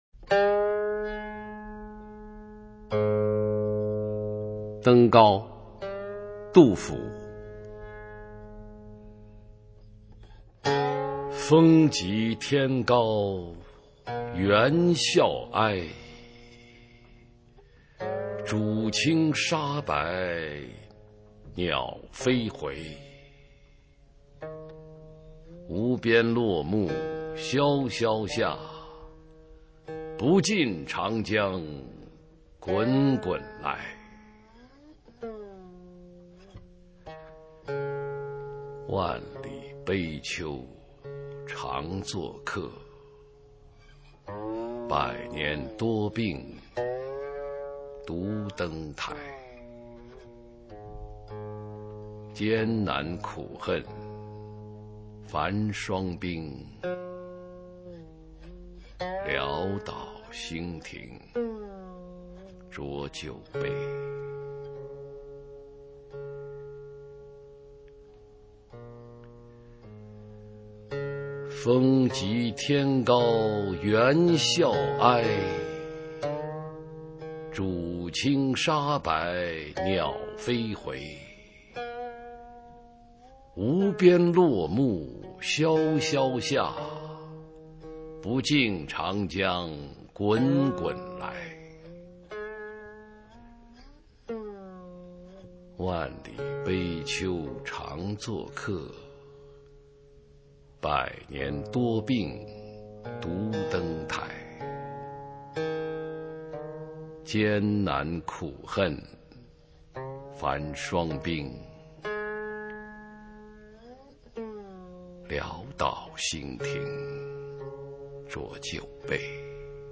普通话美声欣赏：登高　/ 佚名